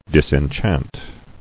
[dis·en·chant]